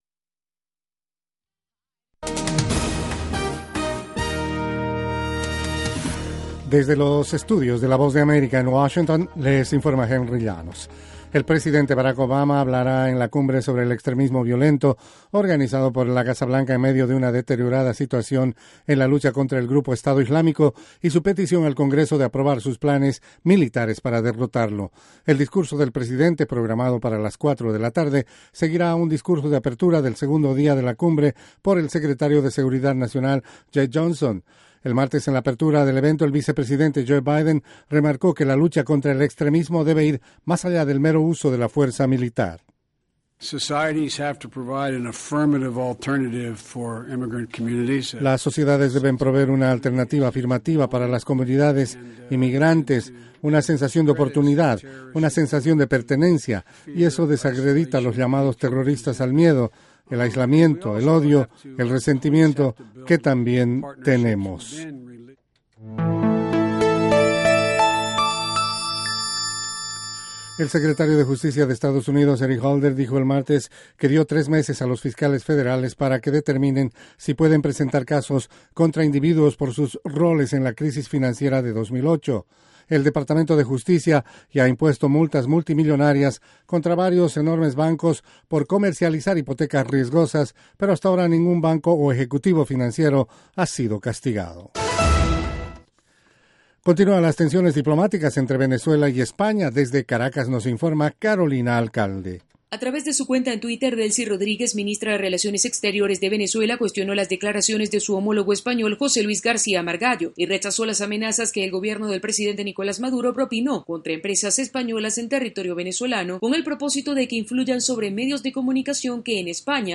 Informativo VOASAT